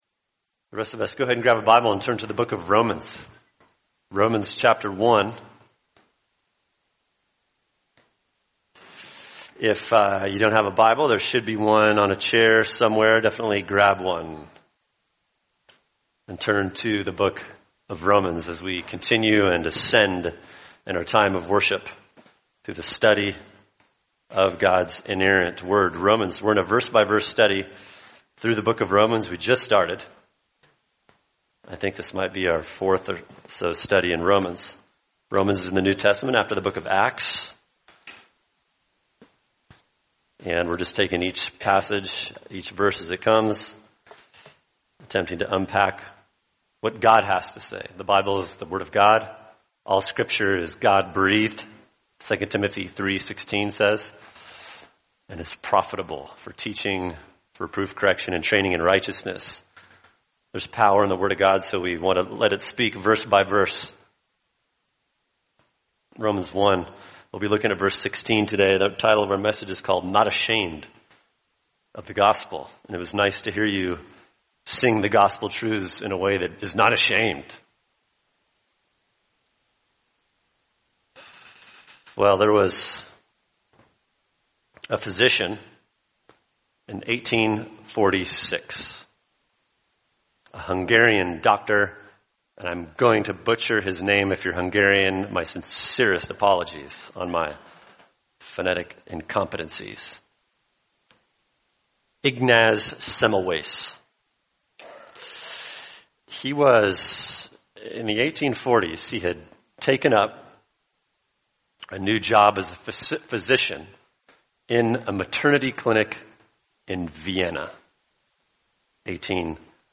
[sermon] Romans 1:16 Not Ashamed of the Gospel | Cornerstone Church - Jackson Hole